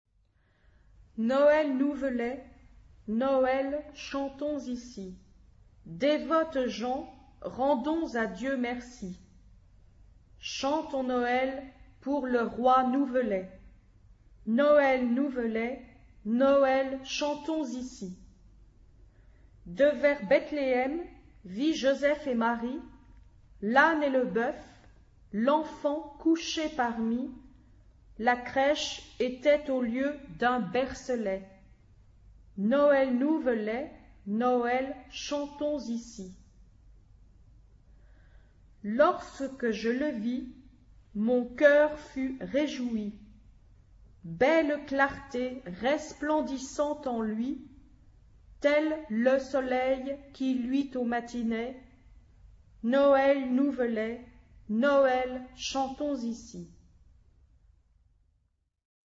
SAB (3 gemischter Chor Stimmen) ; Partitur.
Instrumente: Tasteninstrument (1)
Tonart(en): e-moll